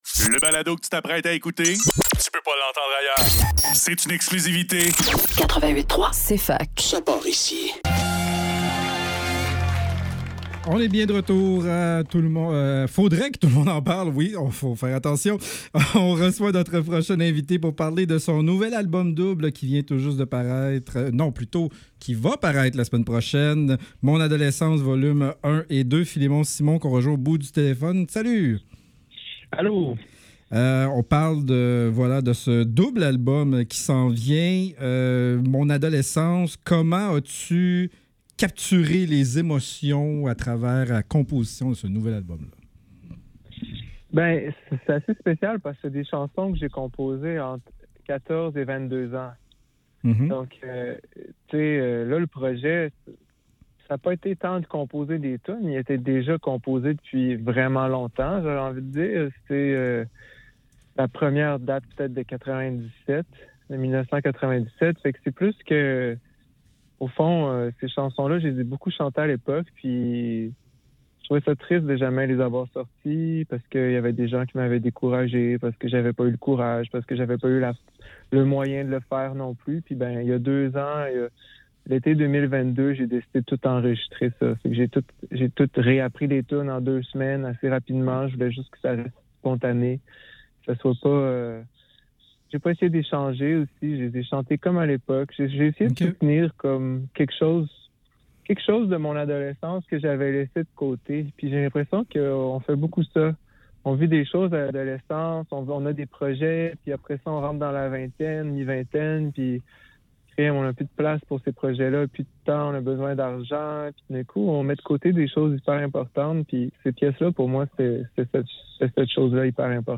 Faudrait que tout l'monde en parle - Entrevue avec Philémon Cimon - 8 octobre 2024